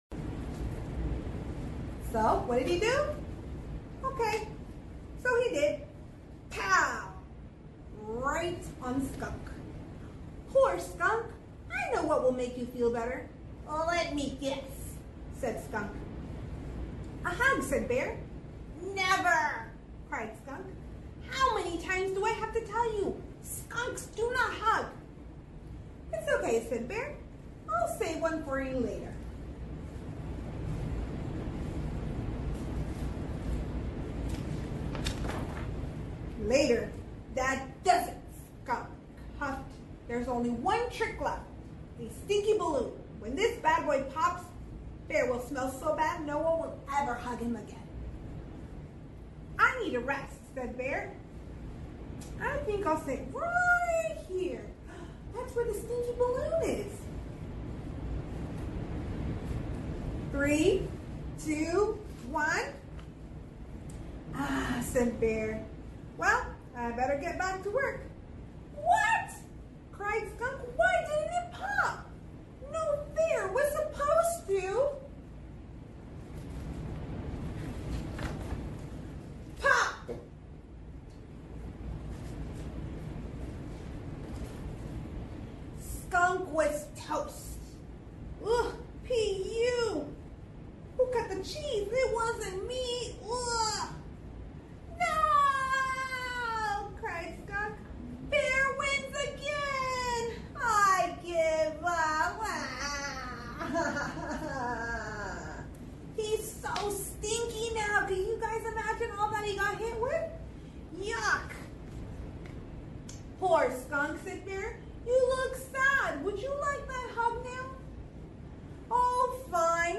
City Council/Public Financing Authority on 2025-06-03 10:00 AM - TEST MEETING - Jun 03, 2025